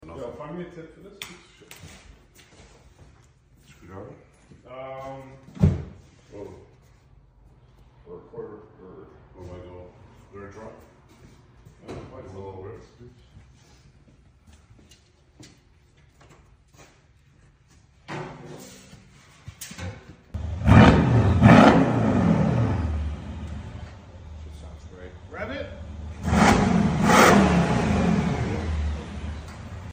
Mercedes Benz CL63 2013 with AMG diffuser custom RES Exhaust valve catback + AMG style tips crazy sound check